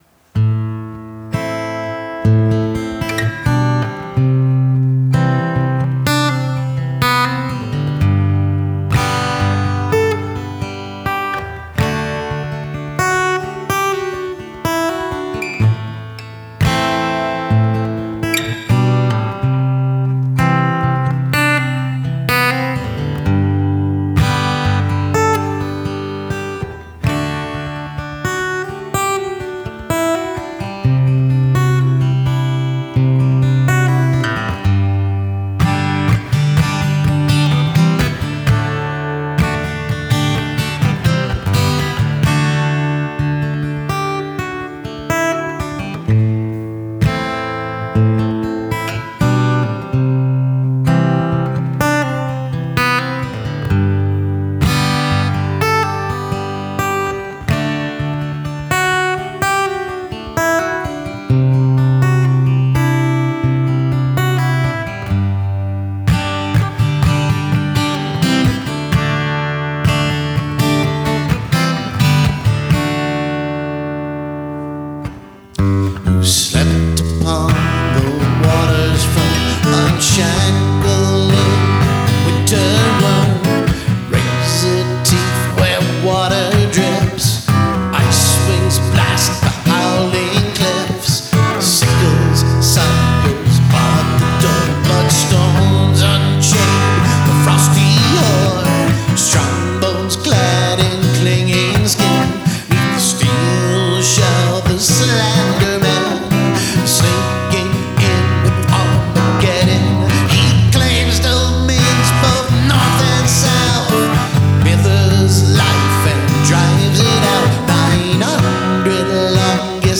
Tempo 126
Scratch
This little shanty started off as a winter poem mashup.